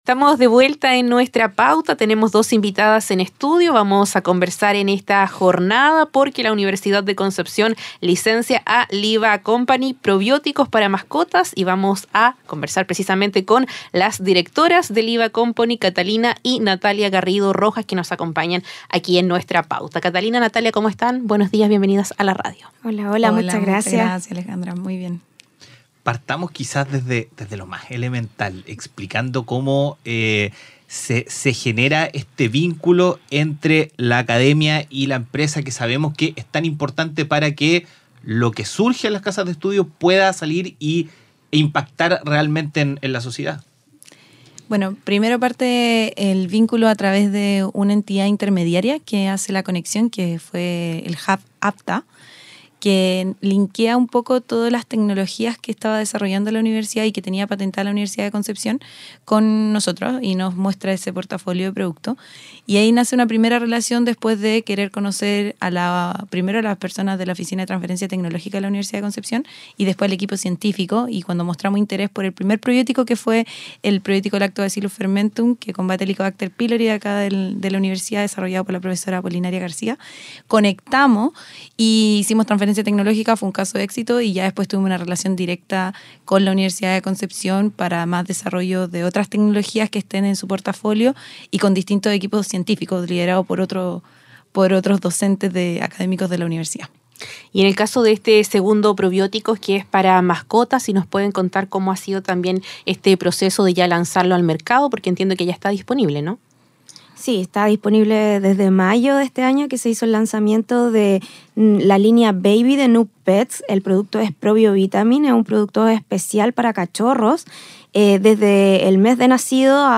26-Entrevista-Probioticos.mp3